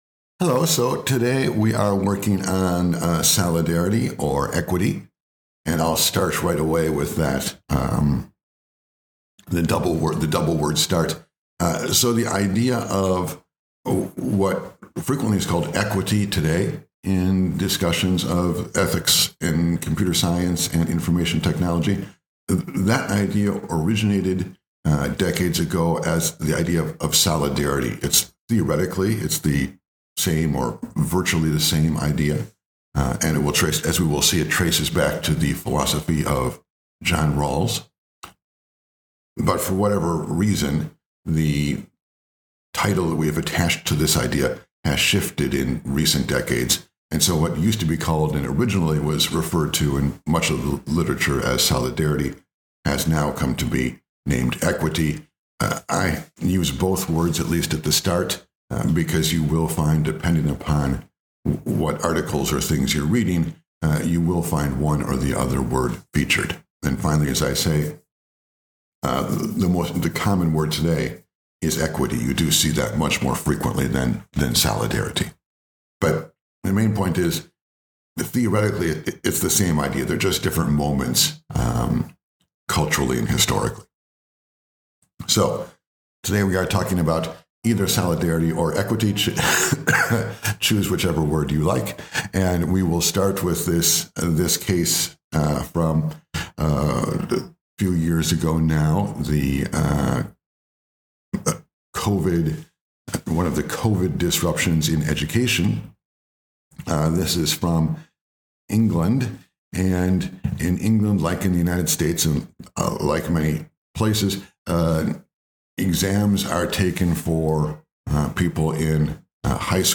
Lecture In this lecture we explore the AI ethics of Solidarity / Equity.